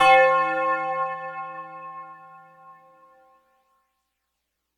Phased Tubular Bell
bell chime chorus clang ding dong echo flange sound effect free sound royalty free Music